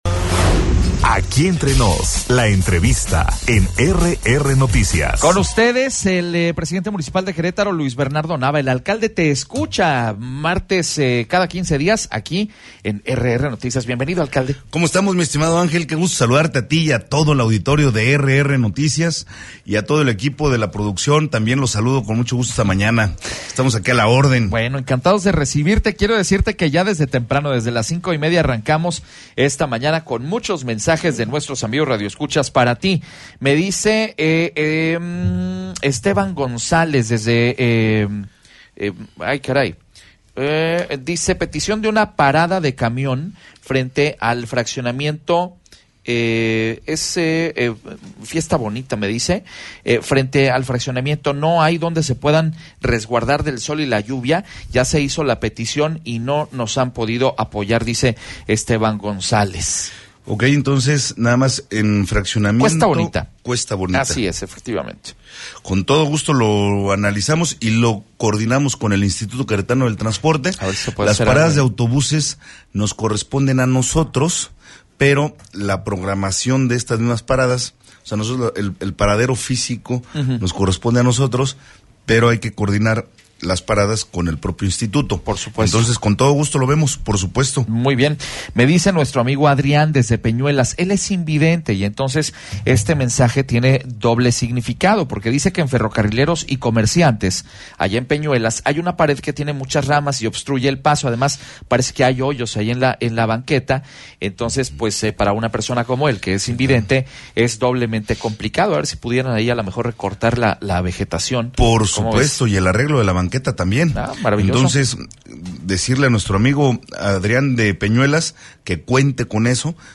ENTREVISTA-ALCALDE-LUIS-NAVA-BAJA.mp3